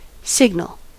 Ääntäminen
US : IPA : [ˈsɪɡn(.ə)l]